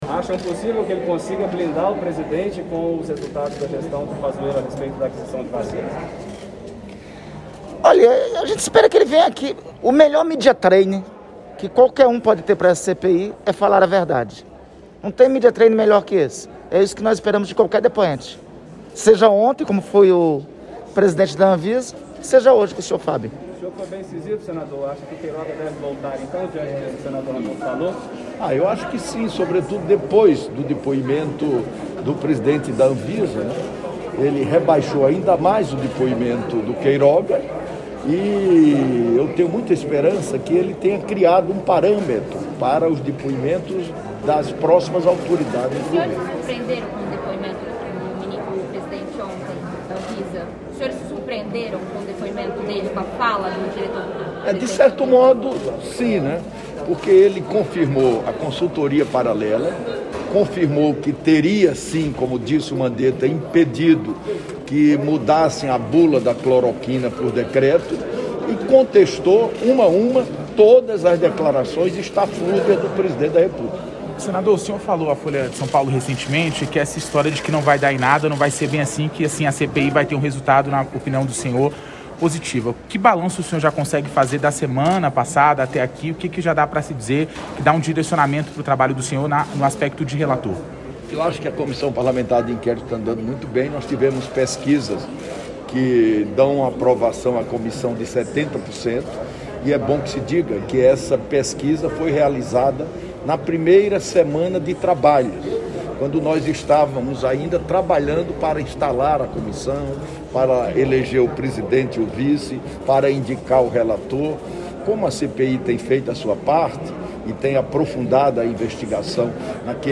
Íntegra da entrevista coletiva com Randolfe Rodrigues e Renan Calheiros
O vice-presidente da CPI da Pandemia, senador Randolfe Rodrigues (Rede-AP), e o relator da comissão, senador Renan Calheiros (MDB-AL), falaram com os jornalistas nesta quarta-feira (12), momentos antes da reunião para ouvir o ex-secretário de Comunicação Social da Presidência da República Fábio Wajngarten.